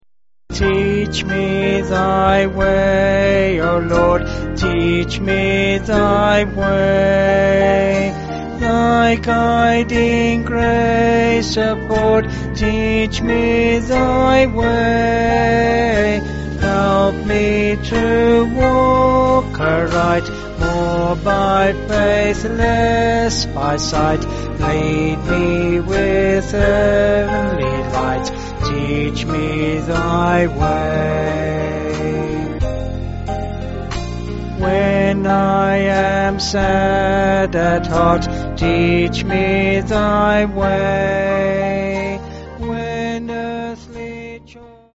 Vocals and Band